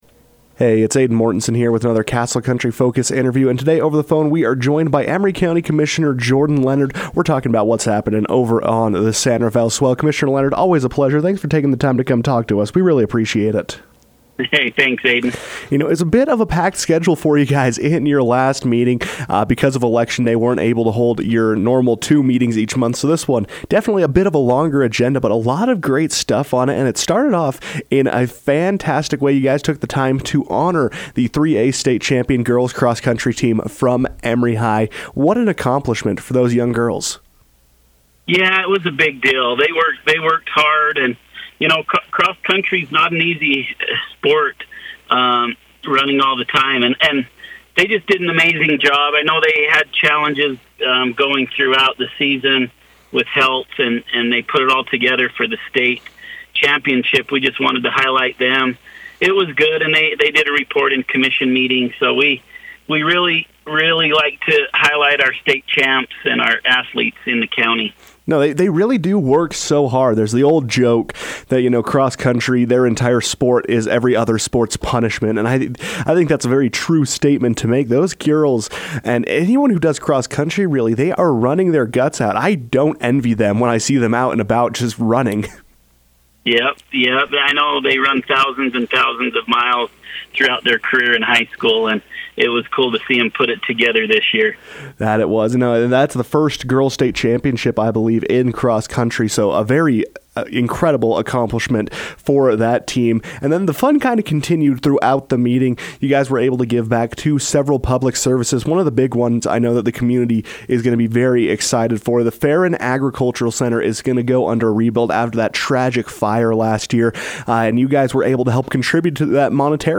Emery County Commissioner Jordan Leonard joined the KOAL newsroom to discuss the commission's end-of-year budget push, donations and the recently approved Emery County Blueprint.